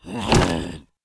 behemoth_attack1b.wav